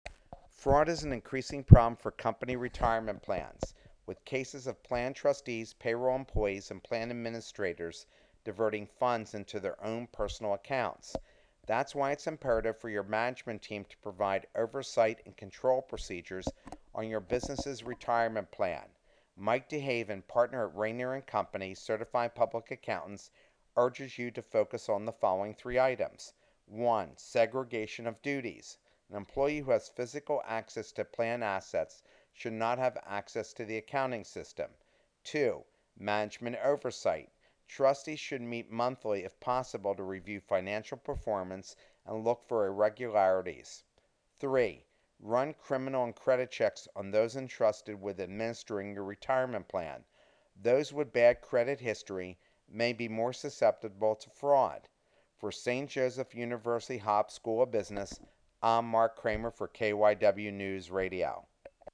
Click here to to listen to the reporter, or see below for the insight that Rainer & Company shared: